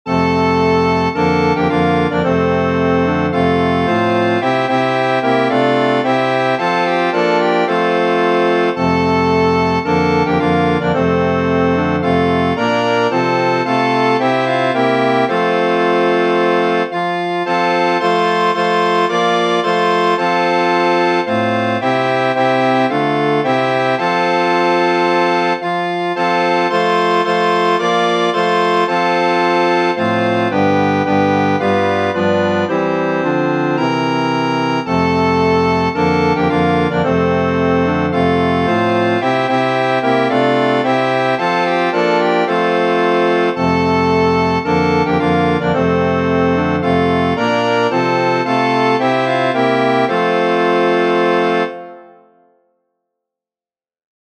Flauta Letra: PowerPoint , PDF Música: PDF , MIDI , MXL Proclamen en los montes, en todas partes y alrededor.